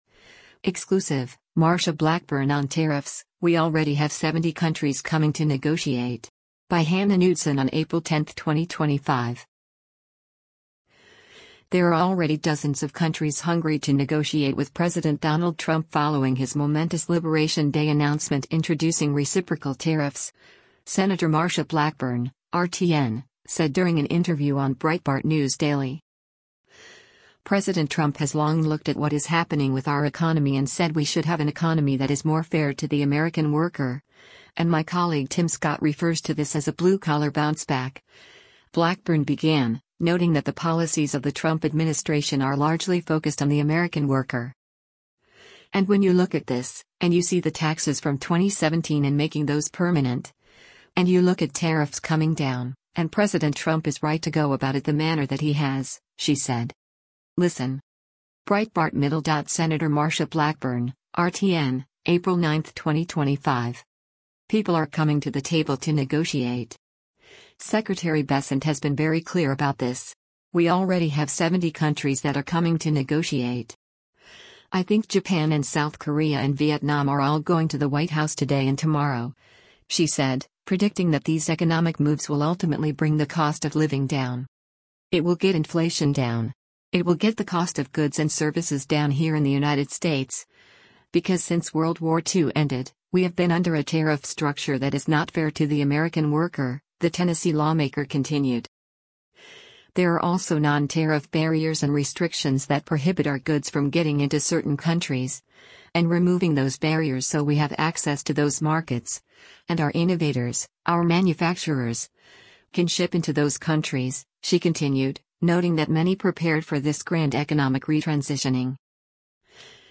There are already dozens of countries hungry to negotiate with President Donald Trump following his momentous “Liberation Day” announcement introducing reciprocal tariffs, Sen. Marsha Blackburn (R-TN) said during an interview on Breitbart News Daily.